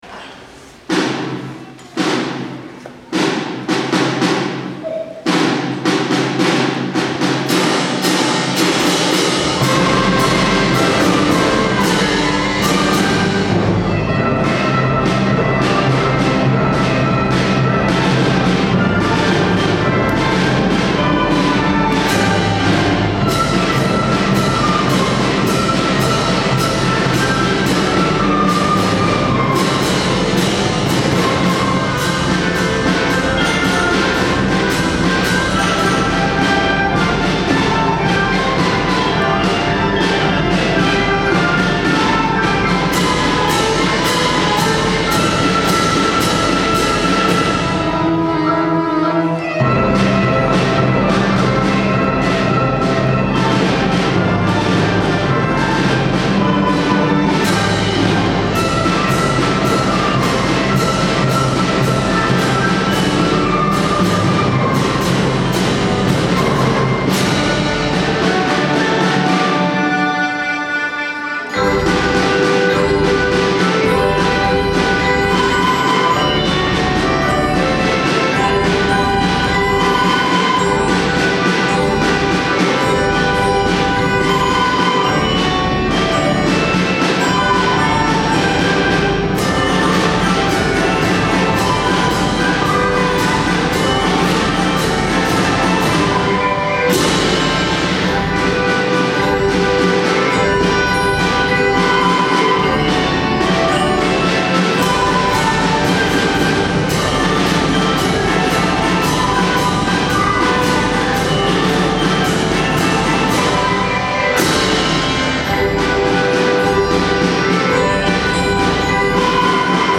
この曲は開校当時から、大切にされてきた曲で、１年間をしめくくるありがとうコンサートで４・５・６年生が毎年演奏しています。
小太鼓だけのドラムマーチに続いて、一気に会場中が盛り上がりました！
見ていた子どもも大人も軽快なリズムに合わせて、思わず体を動かしていました♪
演奏している子どもたちも真剣なまなざしで指揮を見て、一人一人楽しみながら、迫力のある演奏をしていました。